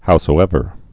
(housō-ĕvər)